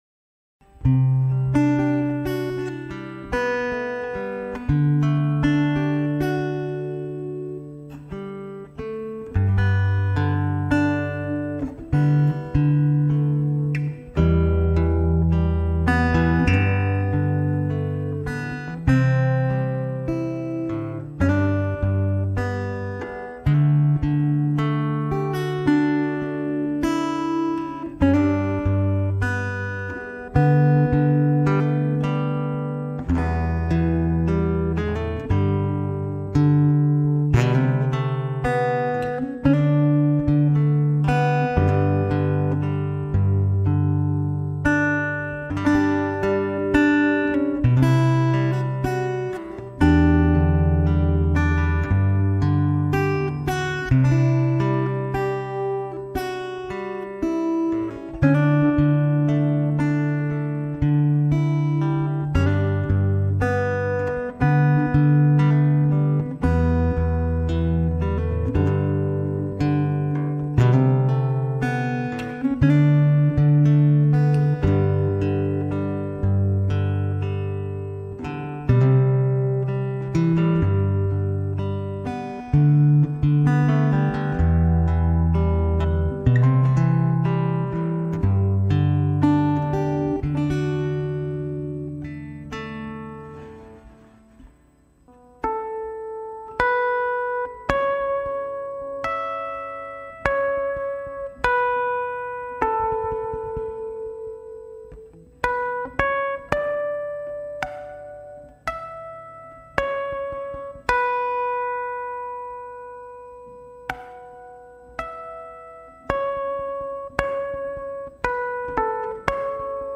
기타 연주